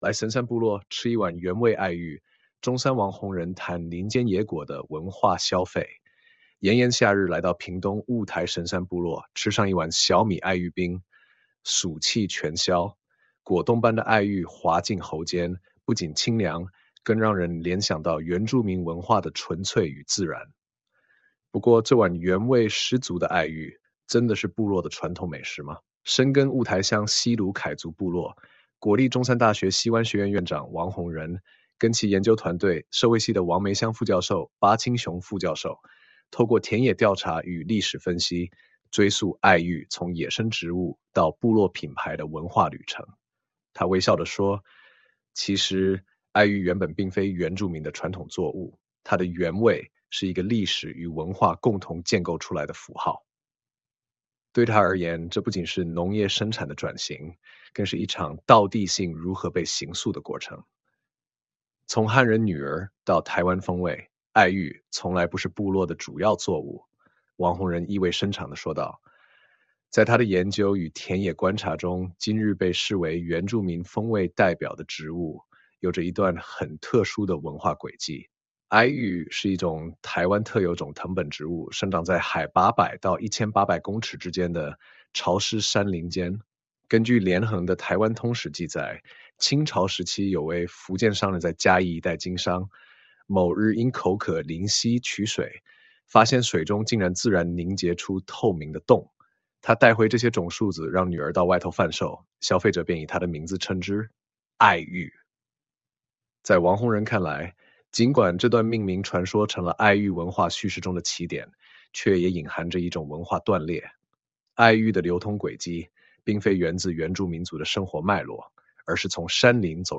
全文朗讀 炎炎夏日，來到屏東霧臺神山部落，吃上一碗小米愛玉冰，暑氣全消。